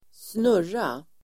Uttal: [²sn'ur:a]